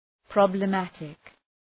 Shkrimi fonetik {,prɒblə’mætık}